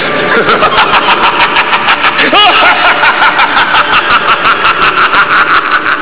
jedlaugh.wav